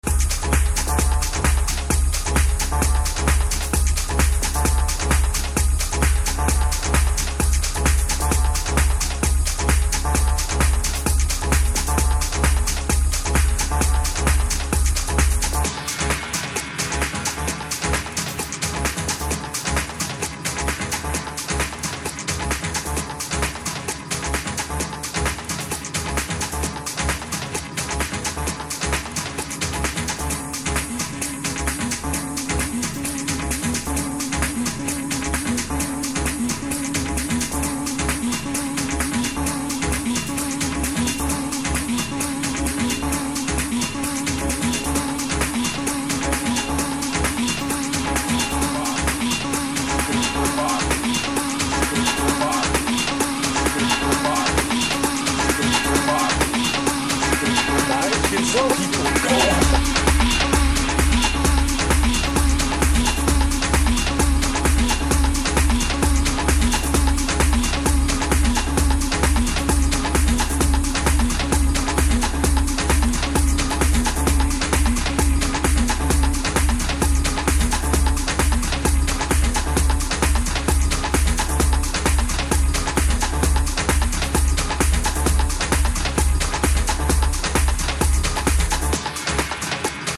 pure detroit dancing moods